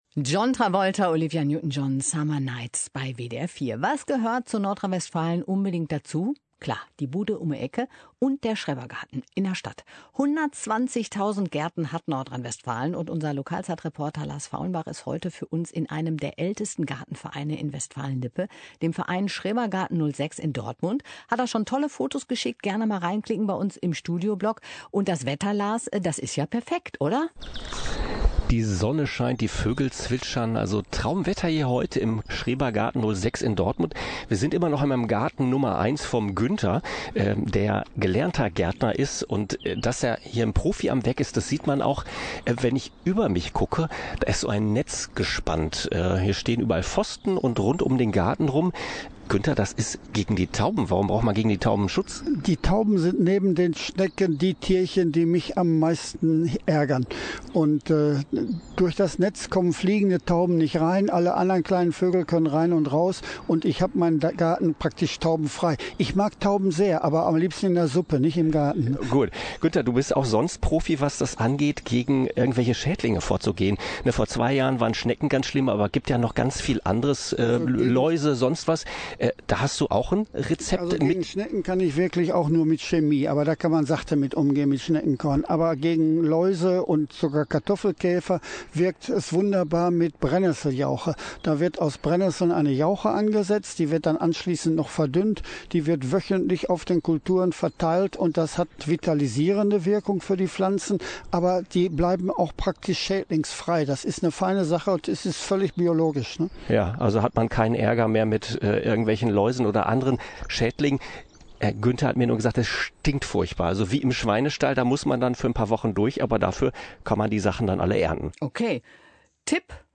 Eine Stunde später stand die Leitung einwandfrei, sodass in der 2. Schalte über 2 wichtige Themen "Kompost" und Brennnesseljauche" informiert wurde.